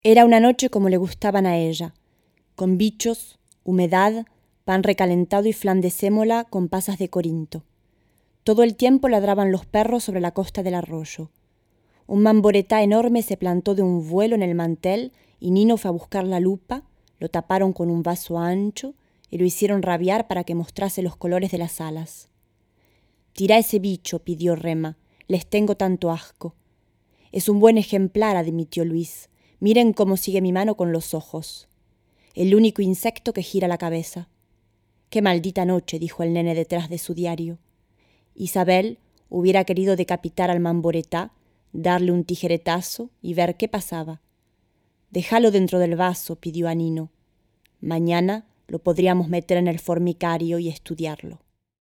Bandes-son
VOIX ESPAGNOL